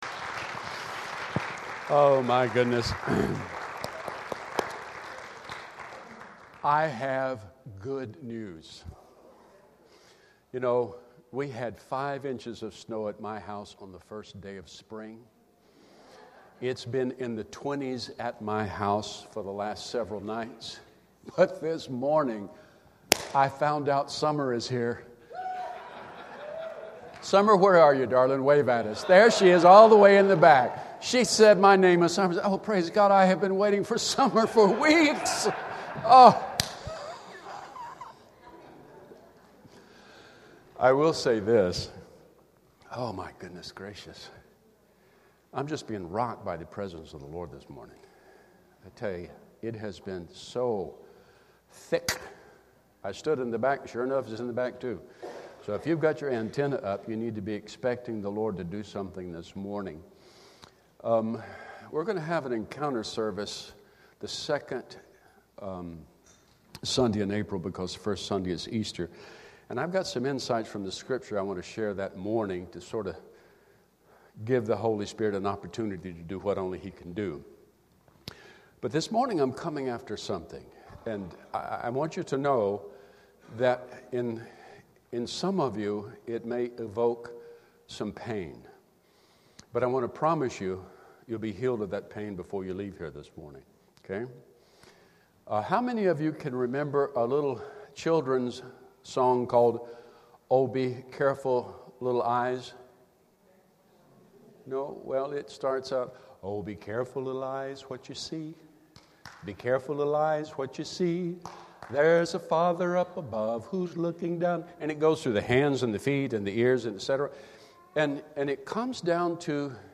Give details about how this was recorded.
At the end of the sermon, he invites those who have been wounded and bound up by the words of others to come up for ministry.